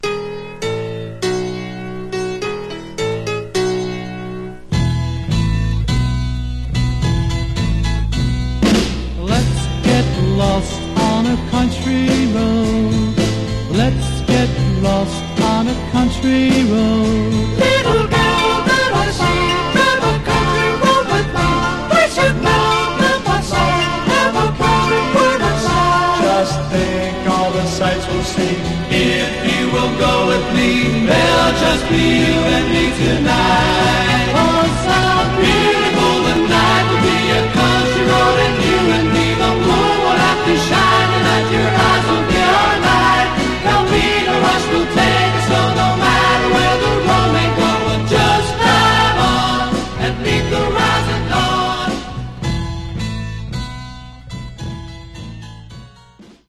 Genre: Sunshine Pop